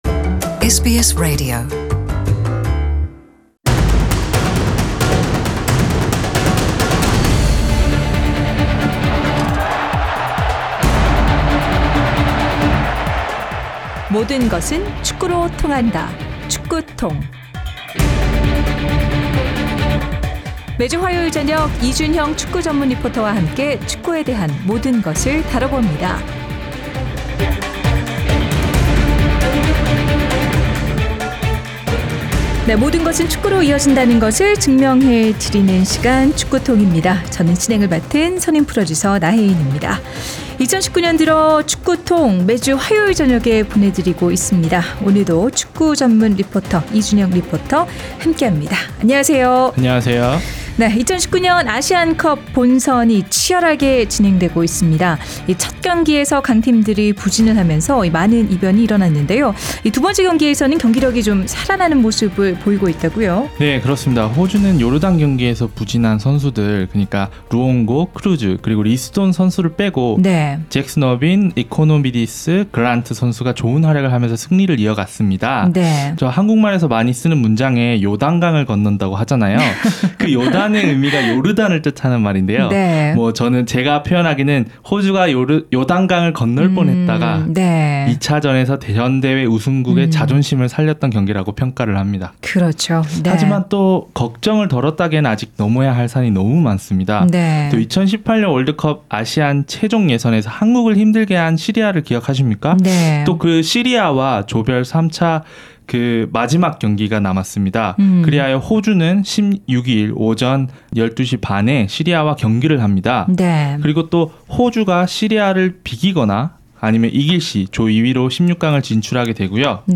Soccer TONG is a sports segment that proves how everything leads to soccer.